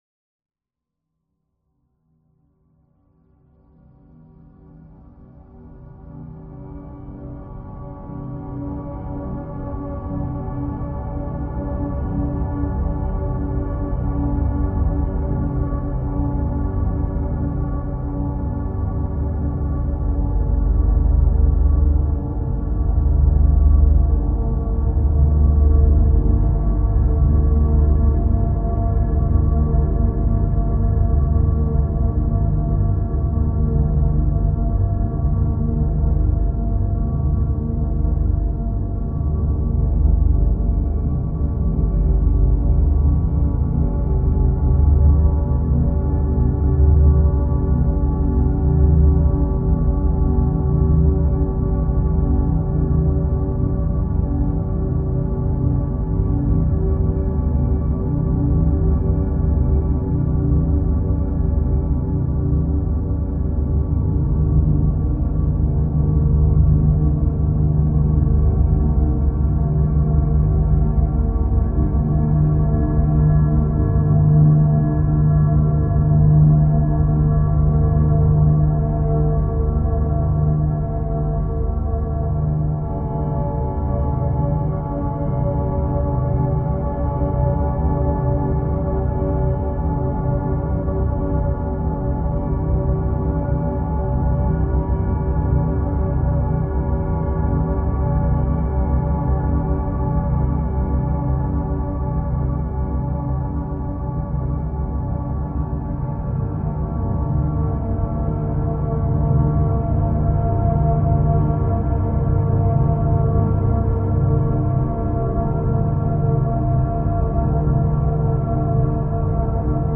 File under: Ambient / Dark Ambient